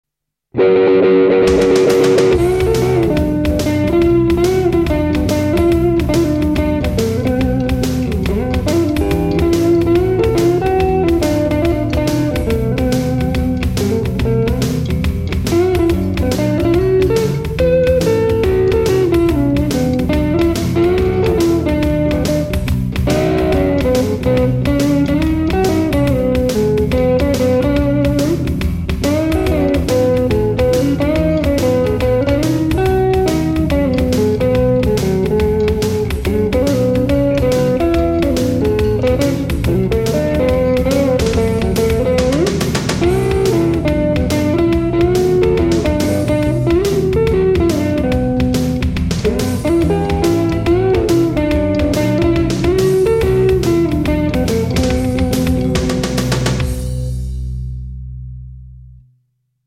15.01.2004 23:18 - Recording Experiments.